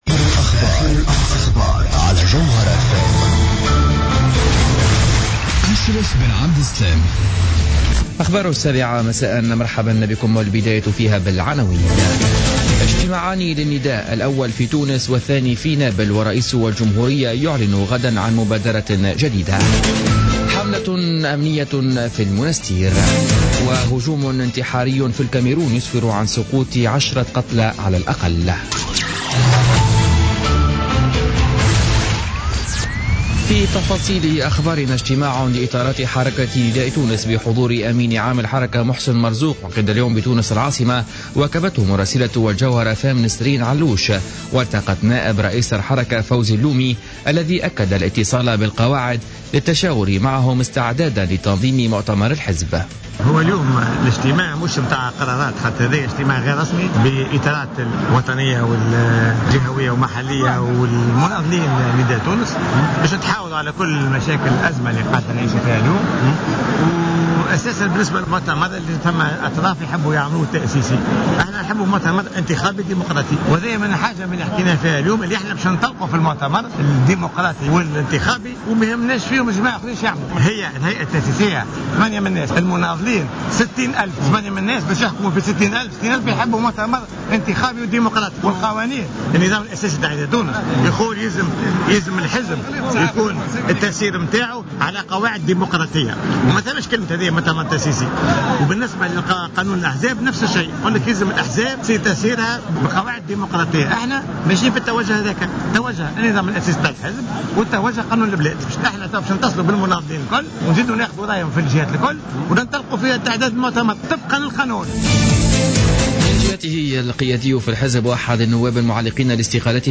نشرة أخبار السابعة مساء ليوم السبت 21 نوفمبر 2015